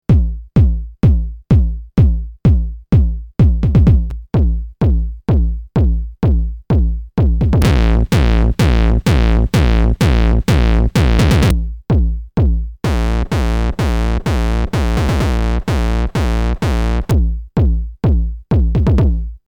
Берется тупо 808 или 909я бочка и цепляется на какой-нибудь дист или что-то типа Sherman Filterbank.
Сначала чистая, потом немного муговского драйва, потом не выключая муг за ним идет фуз Big Muff, потом его отключаю и включаю ProCo Rat.